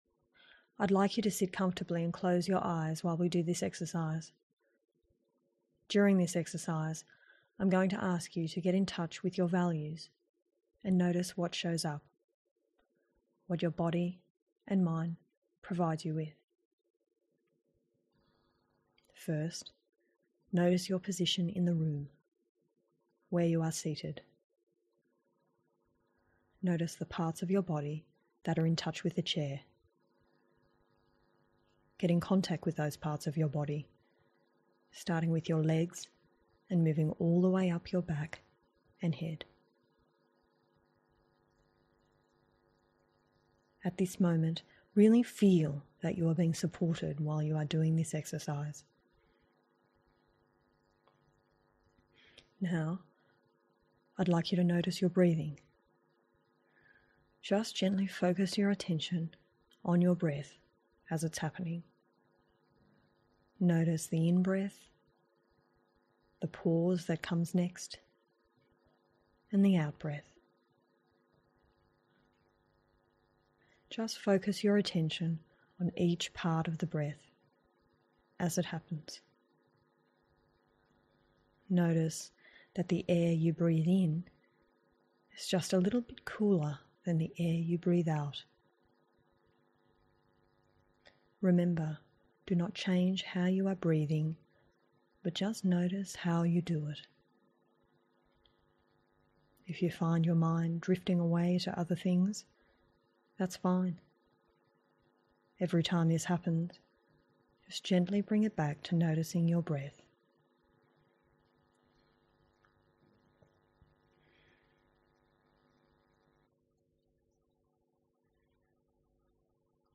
A meditation on values and commitment (audio)